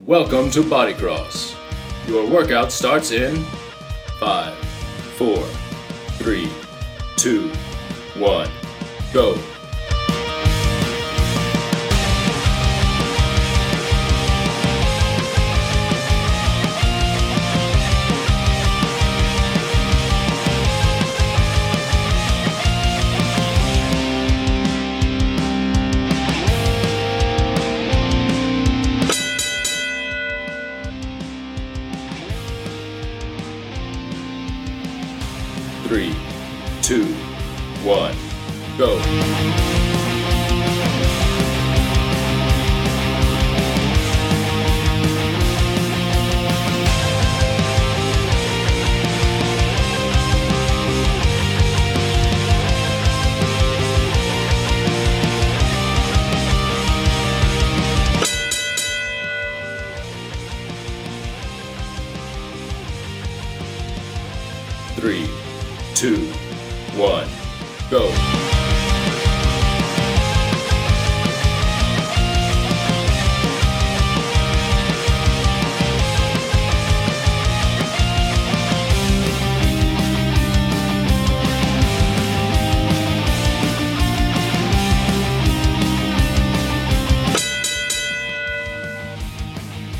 energetic